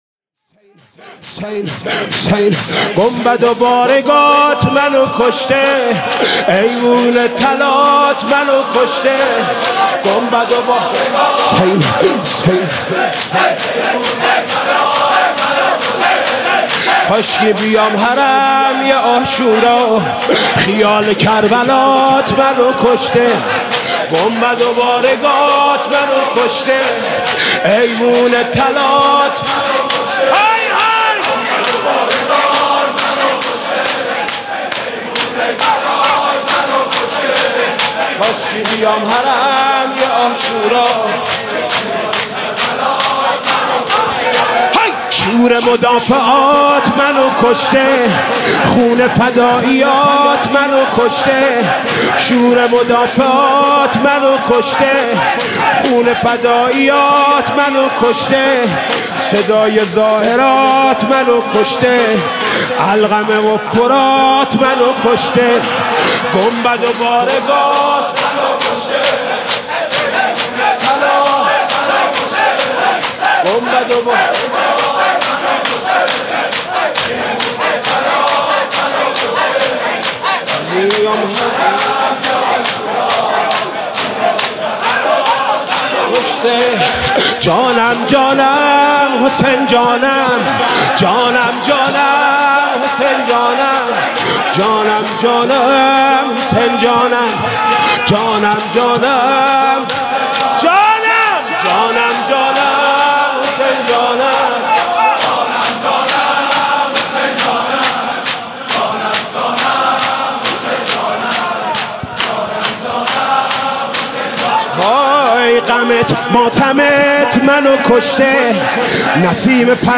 «شهادت امام صادق 1395» شور: گنبد و بارگاهت منو کشته